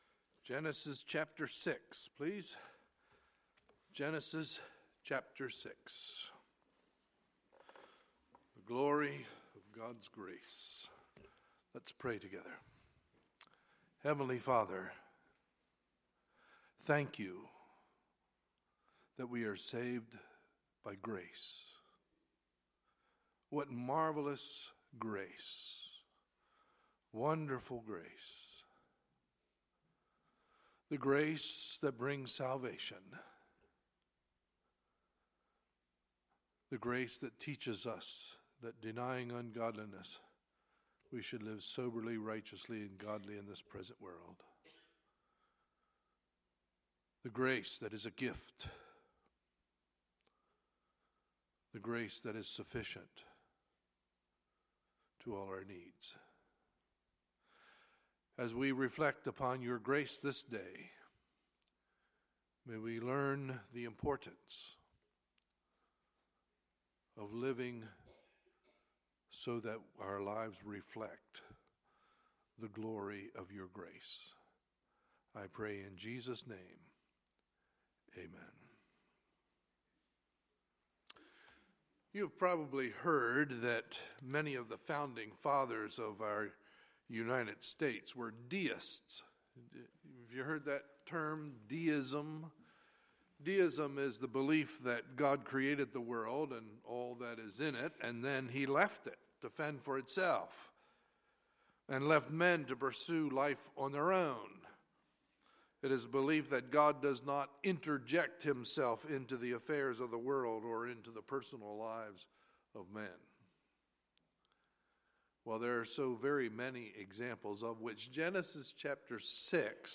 Sunday, March 13, 2016 – Sunday Morning Service